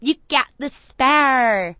gutterball-3/Gutterball 3/Commentators/Poogie/you_got_the_spare.wav at 58b02fa2507e2148bfc533fad7df1f1630ef9d9b
you_got_the_spare.wav